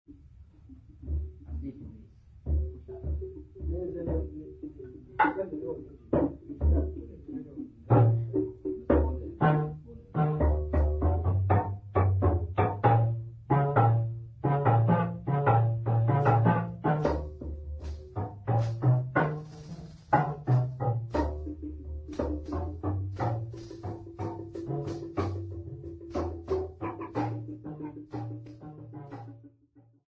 Abafana Benhlonipho
Sacred music South Africa
Marimba music South Africa
Rattle (Musical instrument) South Africa
Africa South Africa Manguzi, KwaZuku-Natal sa
field recordings
Religious song with Marimba, drum and rattles accompaniment.